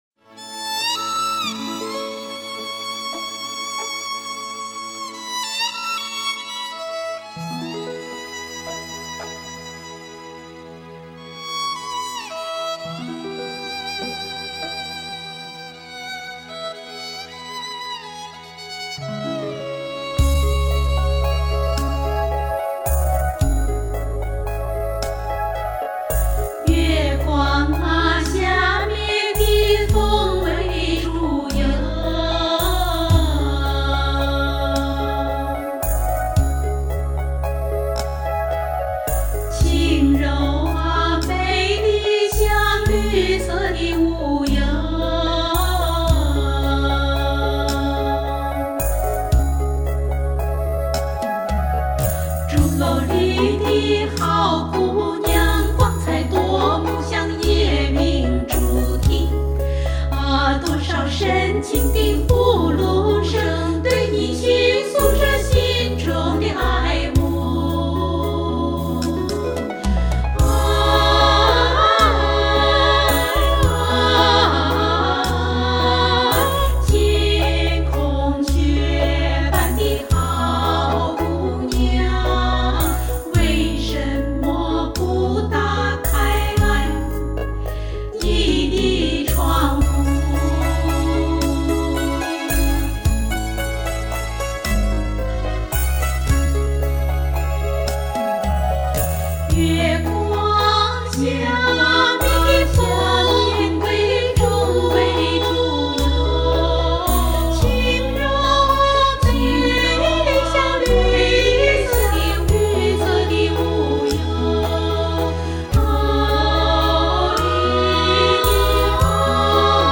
领唱合唱